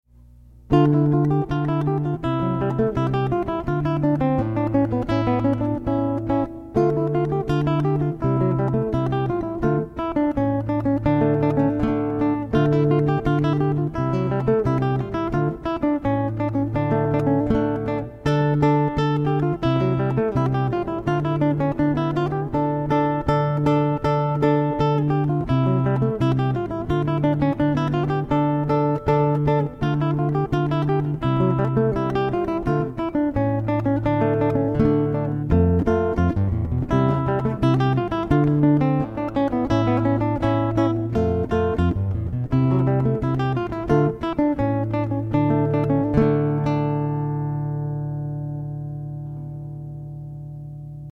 Classical Guitar: